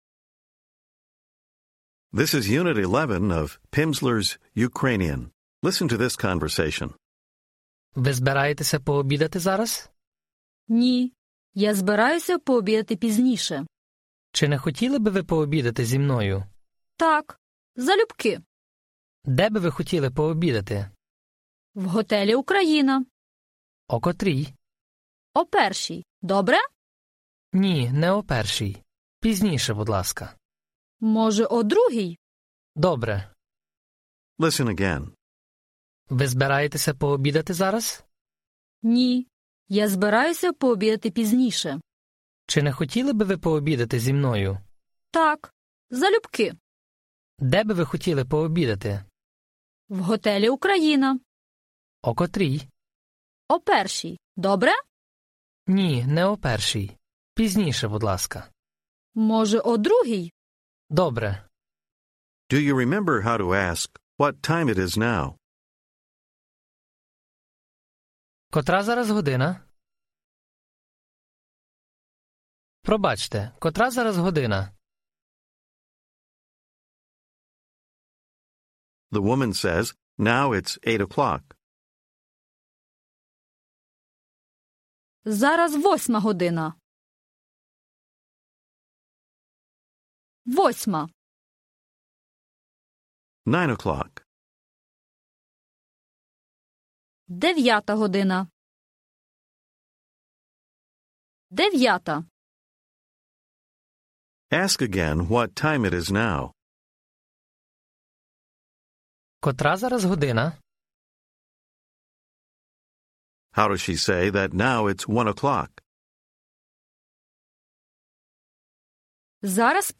Audiobook
This course includes Lessons 11-15 from the Ukrainian Level 1 Program featuring 2.5 hours of language instruction. Each lesson provides 30 minutes of spoken language practice, with an introductory conversation, and new vocabulary and structures.